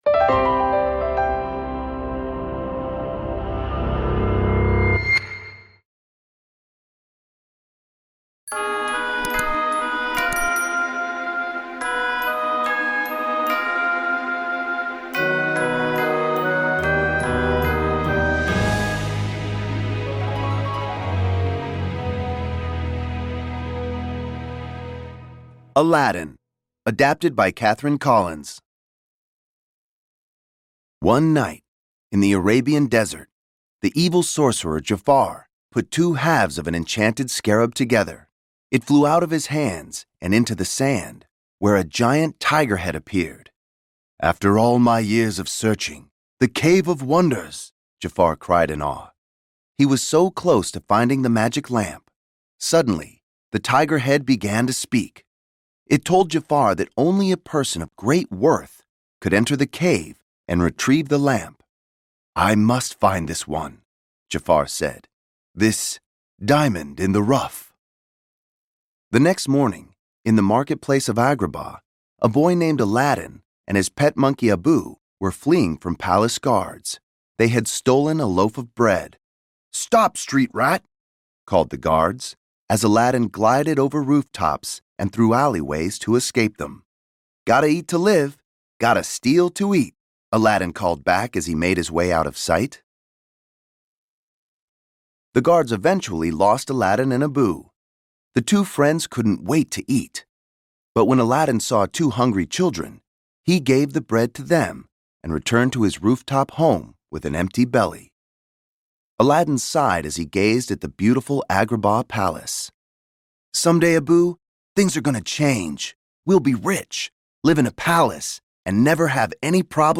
The Magic Shop: H.G. Wells’ Thrilling Story (Audiobook)